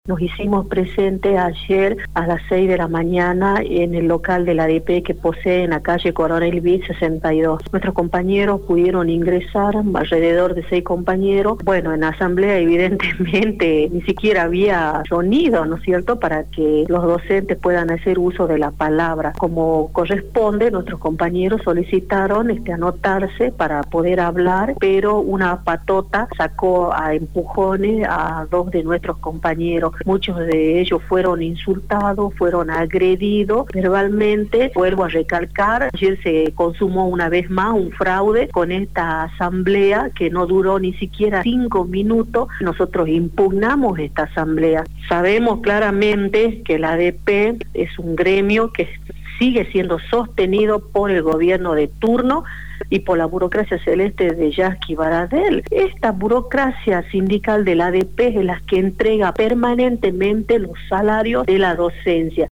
La Diputada Provincial y referente de Tribuna Docente, Norma Colpari, se refirió a las irregularidades que se vivieron en la asamblea para elegir los miembros de la junta electoral que fiscalizara las elecciones de autoridades del gremio, afirmó que su sector impugnó la asamblea.
“Nos hicimos presente ayer a las 6 de la mañana en el local de la ADP (Asociación Docente Provincial) que posee en la Calle Coronel Vidt 62, nuestros compañeros pudieron ingresar, alrededor de seis compañeros, bueno en la asamblea ni siquiera había sonido para que los docentes puedan hacer uso de la palabra. Como corresponde nuestros compañeros solicitaron anotarse para poder hablar pero una patota sacó a empujones a dos de nuestros compañeros, muchos de ellos fueron insultados, fueron agredidos”, manifestó Colpari a Radio Dinamo.